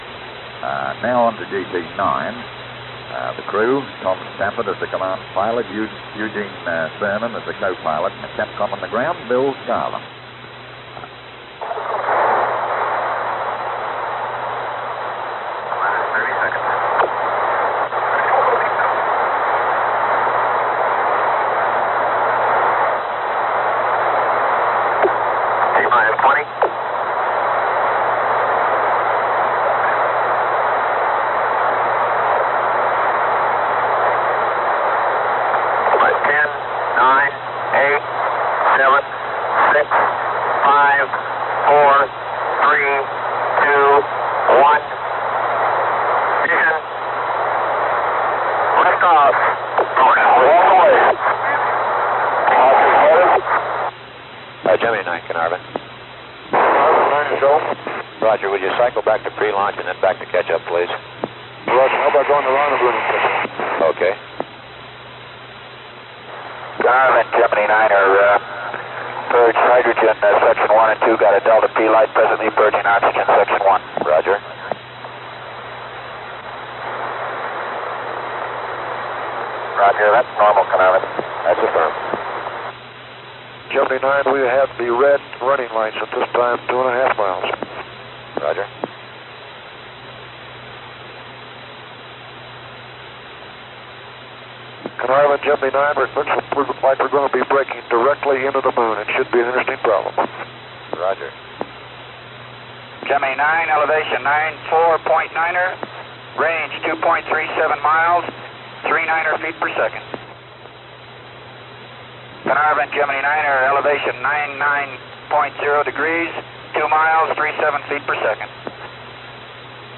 At the end of the Gemini series, a compilation tape of Carnarvon clips from all the manned missions (with the exception of GT10), was produced.